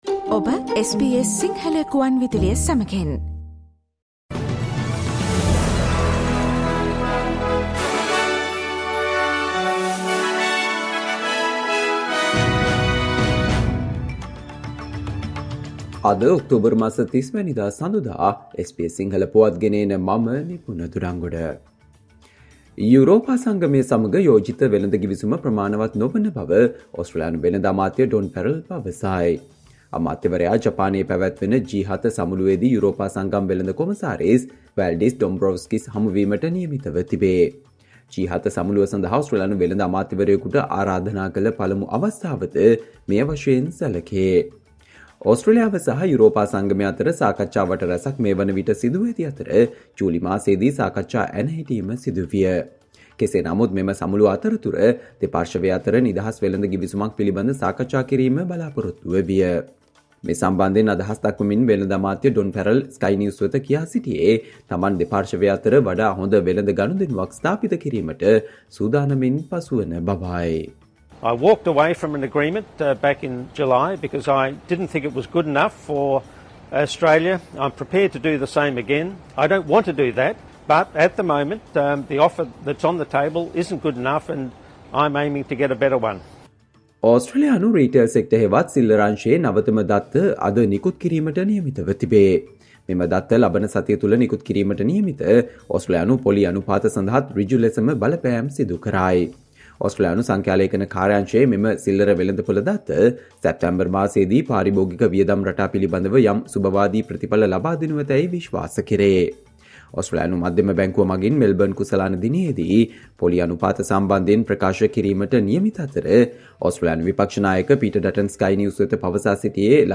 Australia news in Sinhala, foreign and sports news in brief - listen Sinhala Radio News Flash on Monday 30 October 2023.